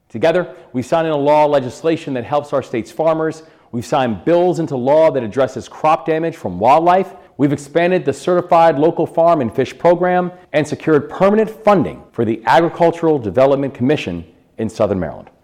Governor Wes Moore addressed the gathering by video with a review of the state’s support for agriculture…